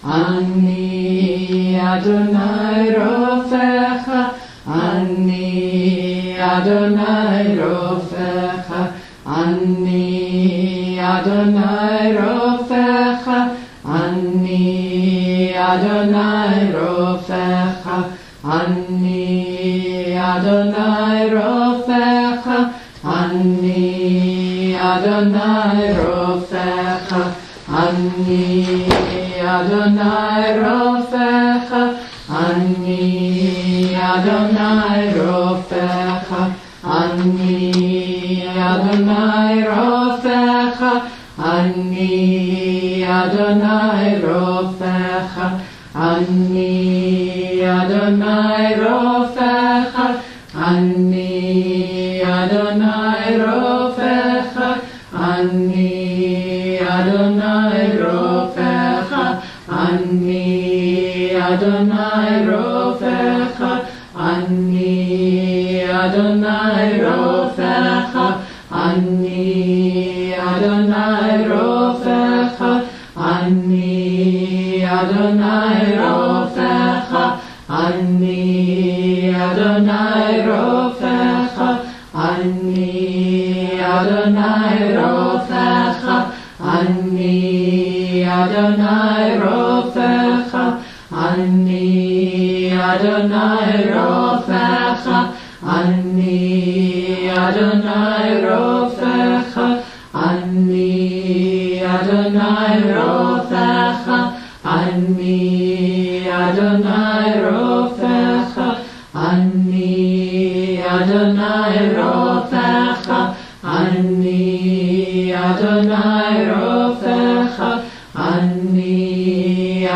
(Recorded at a Temple Isaiah Healing Circle in May 2012)